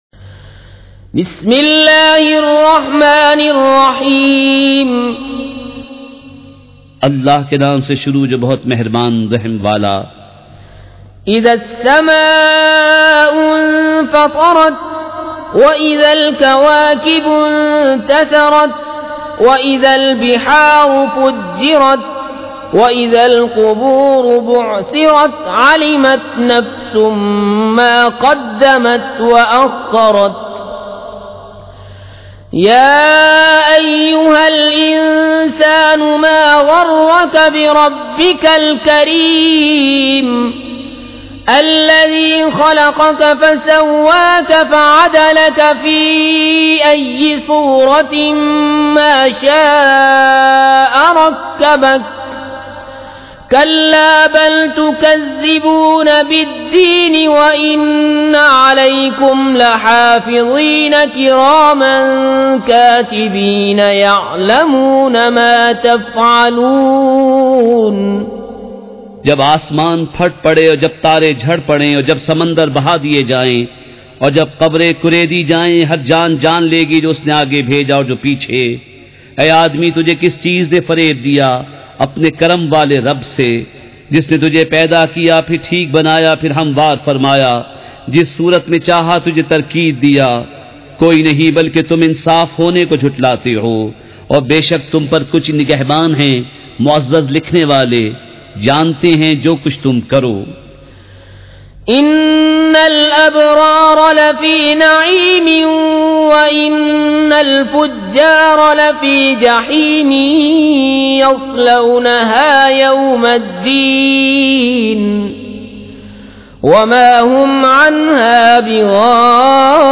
تلاوت
surah-al-infitar-with-urdu-translation.mp3